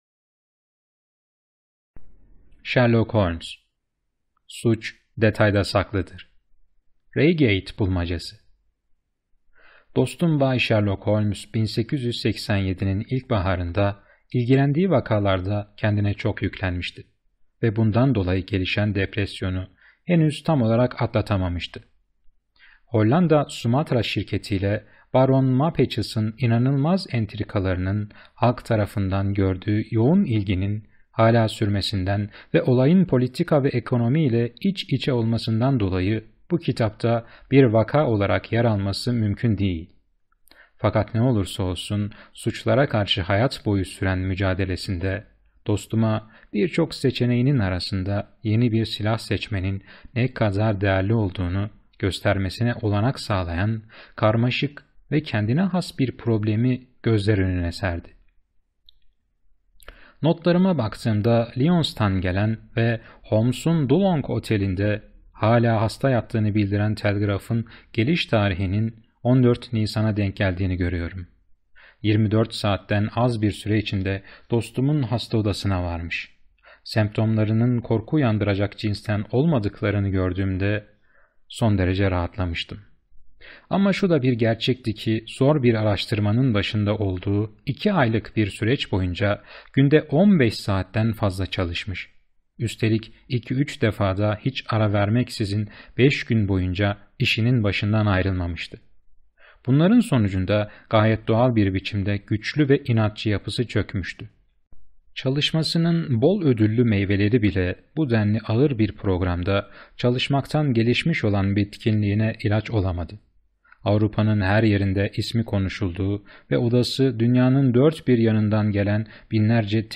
Sherlock Holmes - Reigate Bulmacası - (SESLİ KİTAP) - Kitap Okumaları